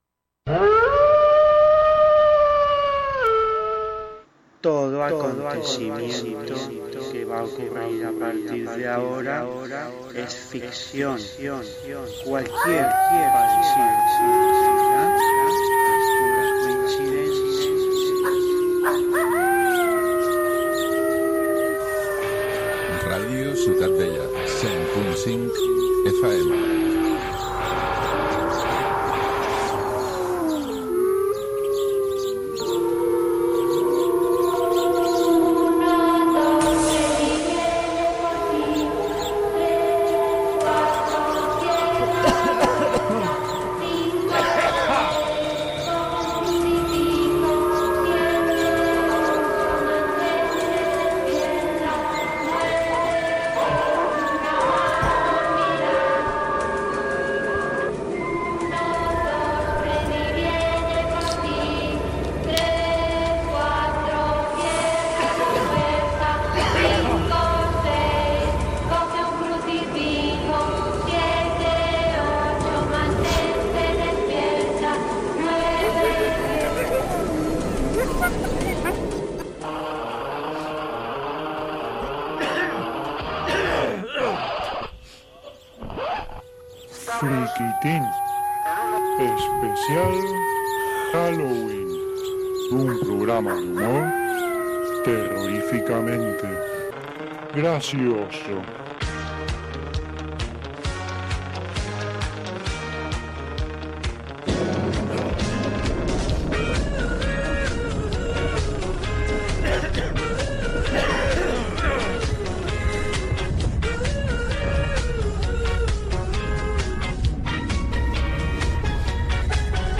Identificació de la ràdio, careta del programa, presentació de l'especial Haloween i dels invitats del grup musical "No son ni las once"
Entreteniment
FM